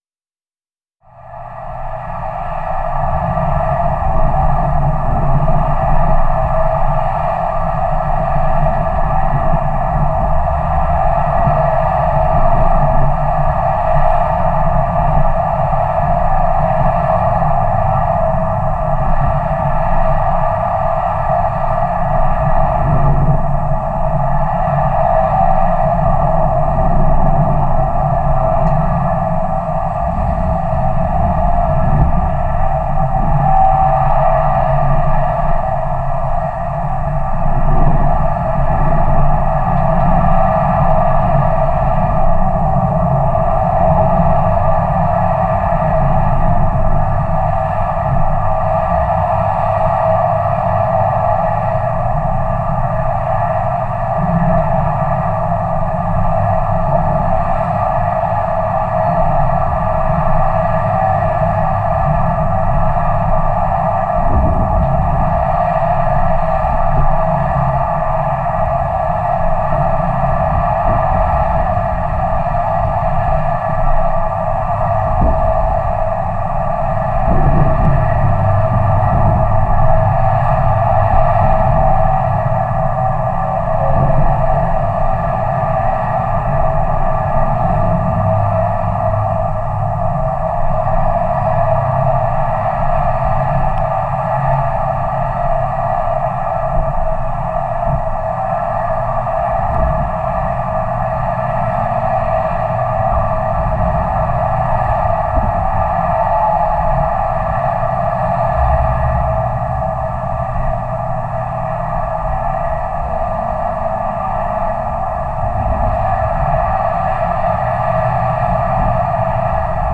Vibration. Road
Sonido vibratorio del puente metálico sobre la autopista.
[ENG] This is the vibratory sound of the metal bridge over the highway.
vibration-road.wav